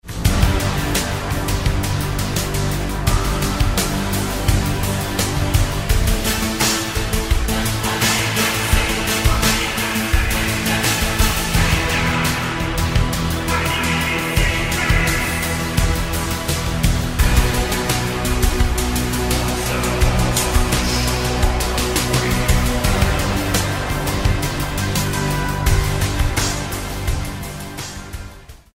orchestral background sound